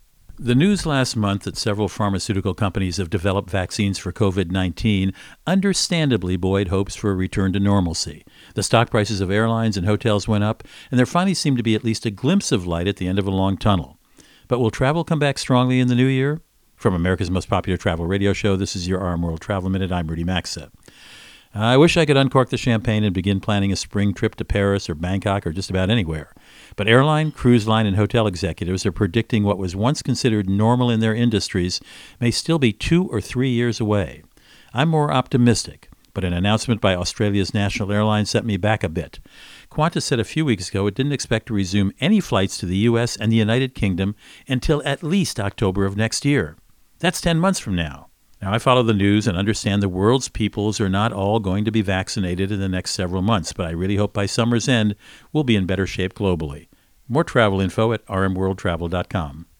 America's #1 Travel Radio Show
Friday, 4 Dec 20 .. Co-Host Rudy Maxa | Should We Be Celebrating?